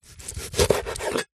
Звуки лимона, лайма
Откройте для себя яркие звуки лимона и лайма: сочное разрезание, брызги сока, хруст свежих долек.
Применяем крупный нож